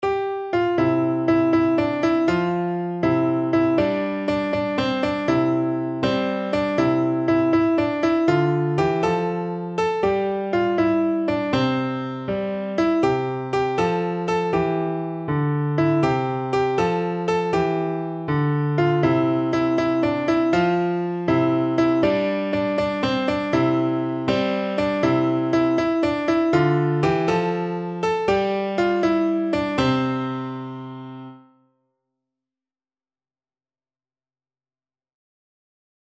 No parts available for this pieces as it is for solo piano.
6/8 (View more 6/8 Music)
Lively
Piano  (View more Easy Piano Music)